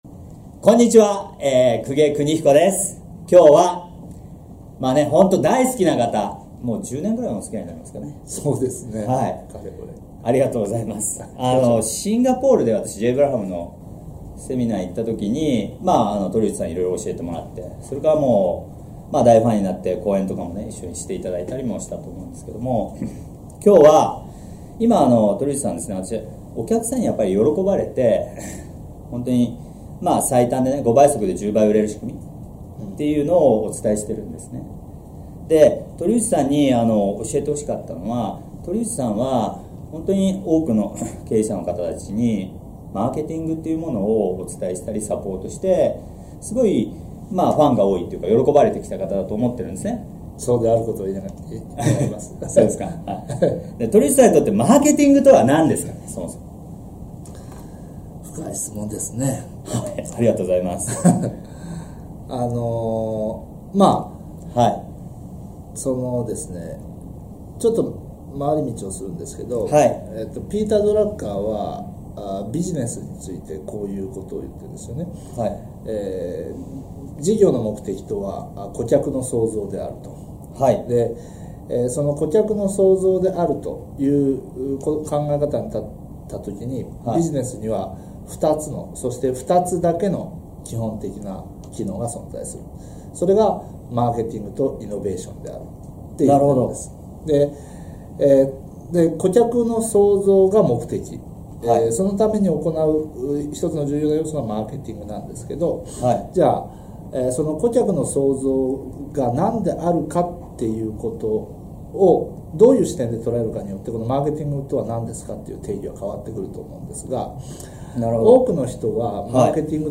【特典 対談動画】